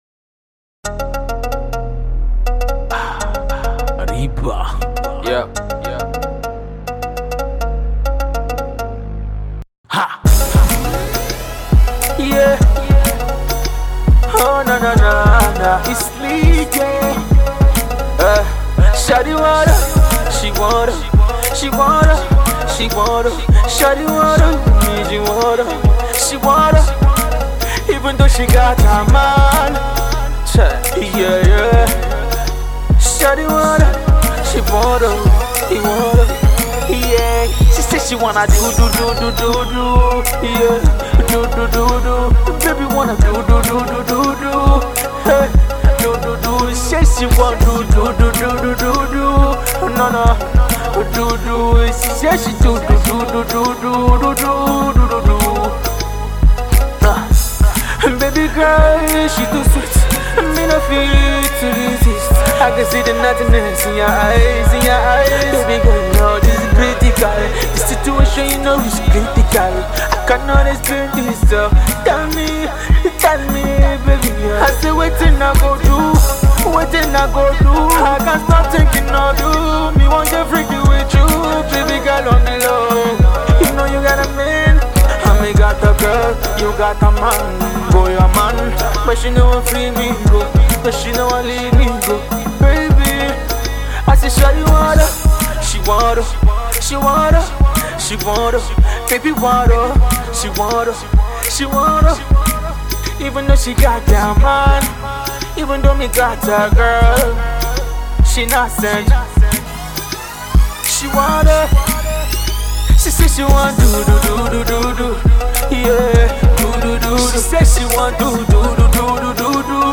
A Pop Freestyle for the Clubs